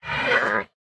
Minecraft Version Minecraft Version 1.21.4 Latest Release | Latest Snapshot 1.21.4 / assets / minecraft / sounds / mob / panda / cant_breed4.ogg Compare With Compare With Latest Release | Latest Snapshot
cant_breed4.ogg